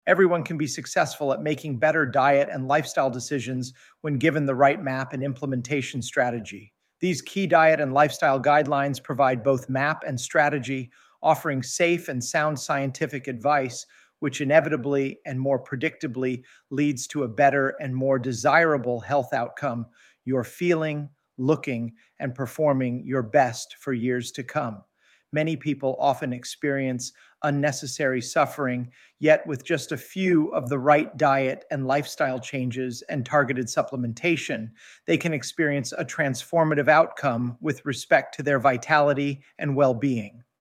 Sample audio EXCERPTS from the audiobooks: